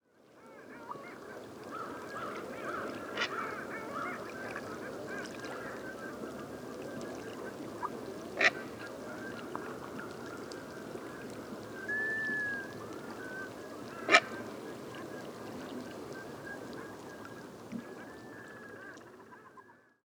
Grand héron – Ardea herodias
Cri en vol Quelques individus se nourrissaient dans la baie à marée basse. Parc national du Bic – Secteur Pointe-aux-Épinettes, Rimouski-Neigette, QC 48°21’38.2″N 68°46’50.6″W. 5 mai 2019. 5h30.